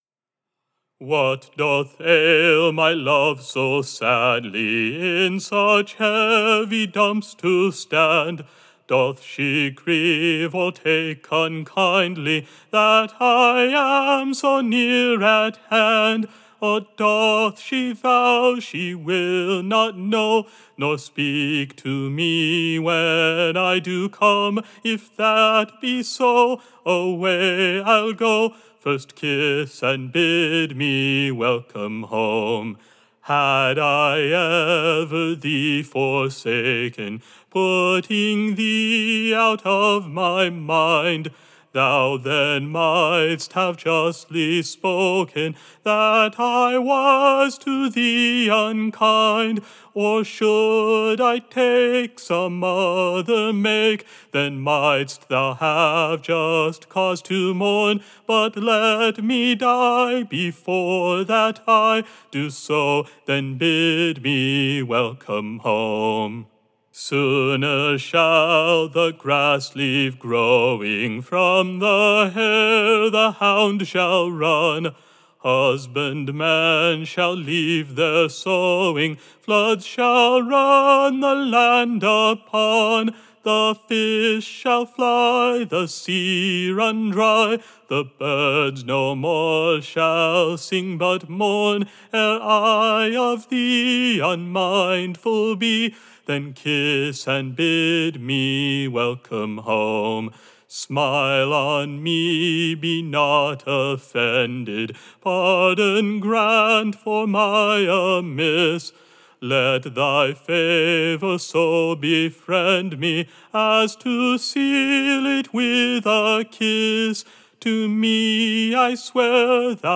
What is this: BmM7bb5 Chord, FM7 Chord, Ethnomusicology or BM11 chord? Ethnomusicology